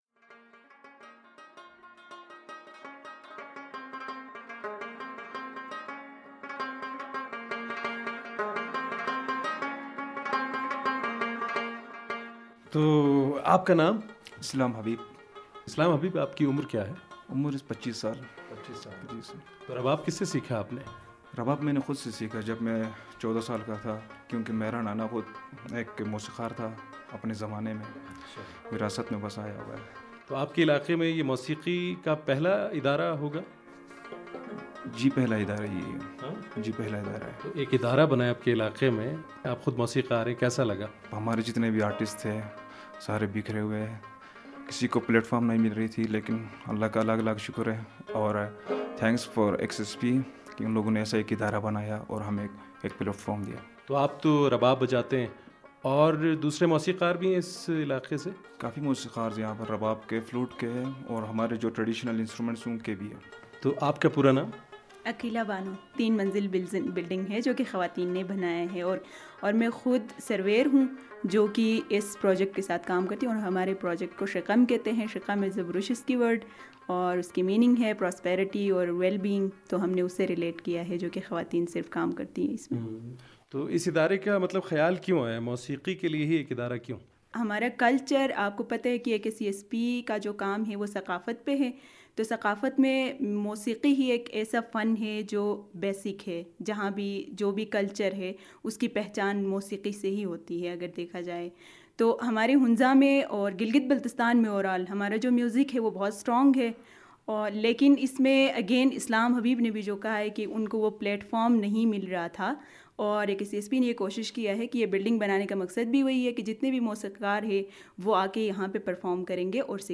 اور اس کی ادارے میں کام کرنے والی محنت کش خواتین اور موسیقاروں سے گفتگو کی